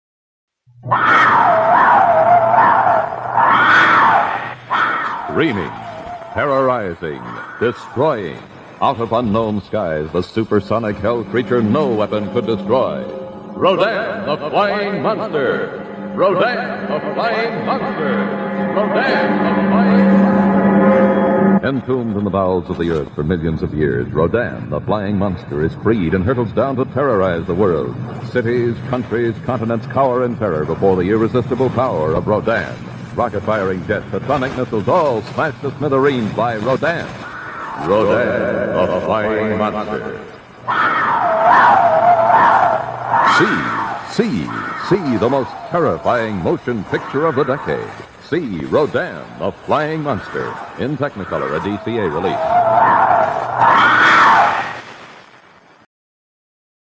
The Flying Monster Radio Spots
20, 30, and 60 seconds radio spots for Rodan!